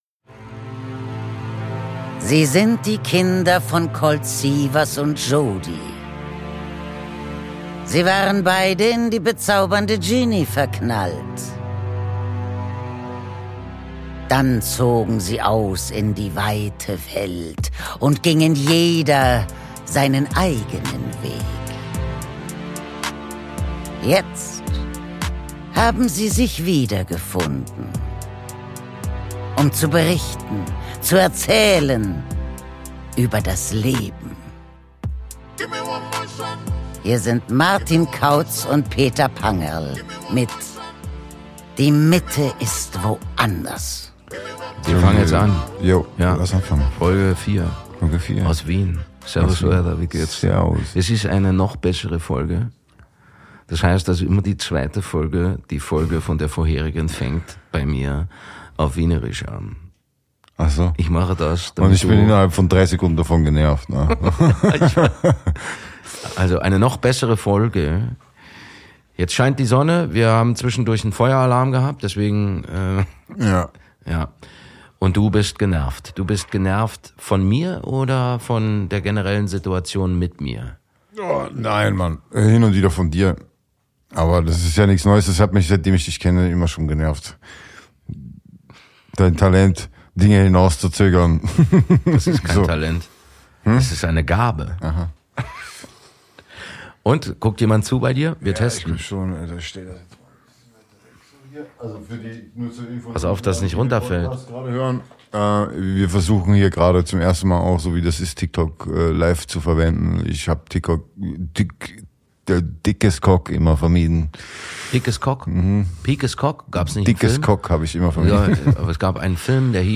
Aus dem 25h Hotel im Wiener Museumsquartier